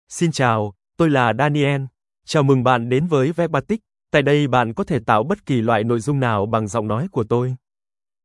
MaleVietnamese (Vietnam)
Voice sample
Male
Daniel delivers clear pronunciation with authentic Vietnam Vietnamese intonation, making your content sound professionally produced.